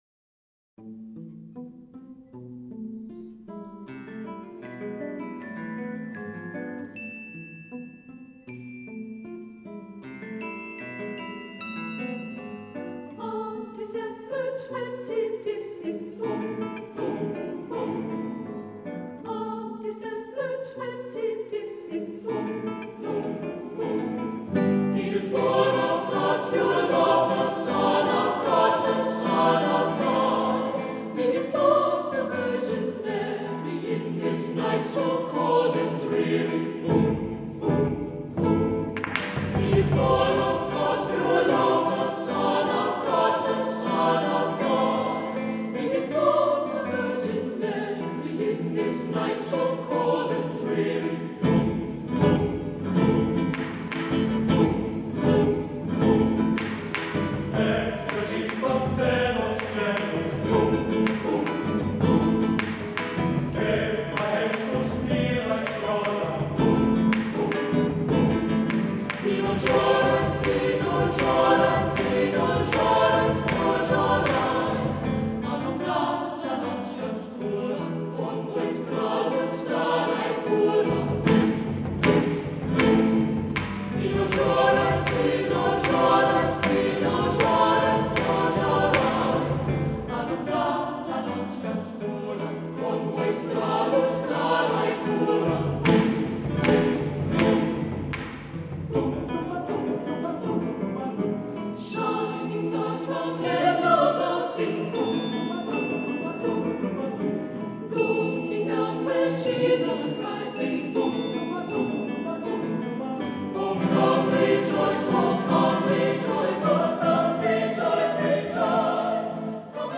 Guitar
Native American Flute